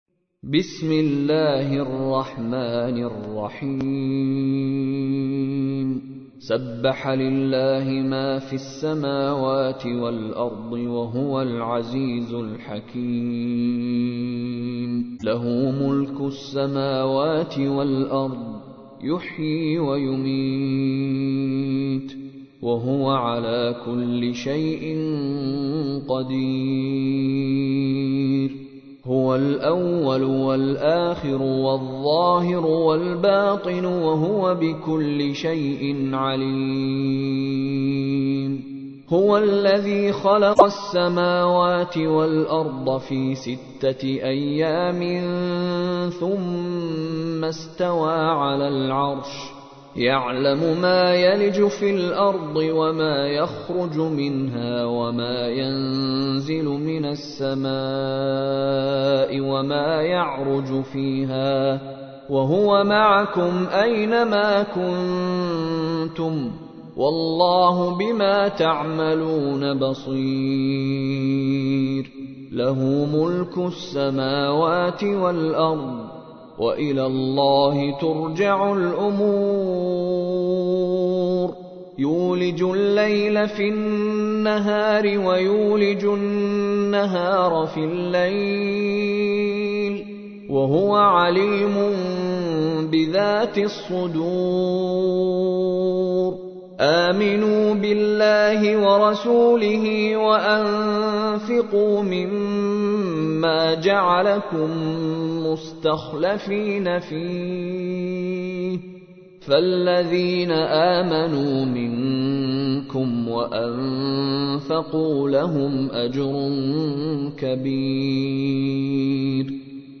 تحميل : 57. سورة الحديد / القارئ مشاري راشد العفاسي / القرآن الكريم / موقع يا حسين